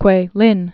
(kwālĭn)